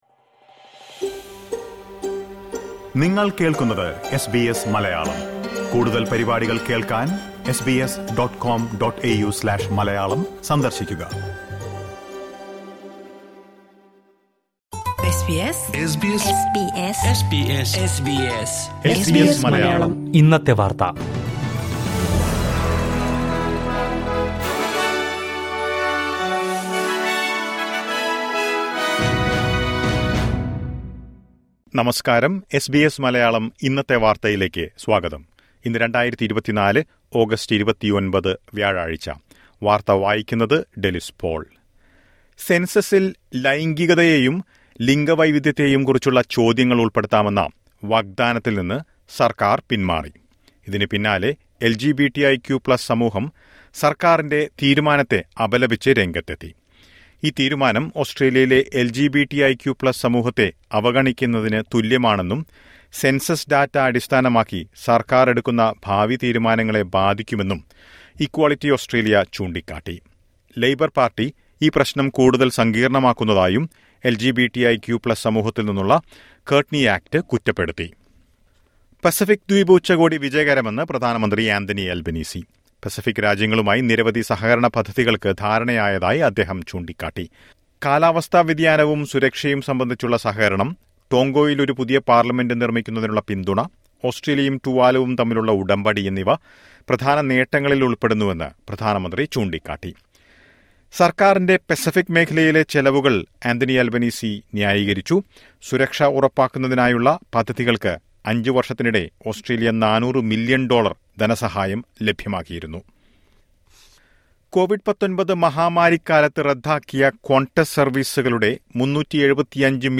2024 ഓഗസ്റ്റ് 29ലെ ഓസ്‌ട്രേലിയയിലെ ഏറ്റവും പ്രധാന വാര്‍ത്തകള്‍ കേള്‍ക്കാം...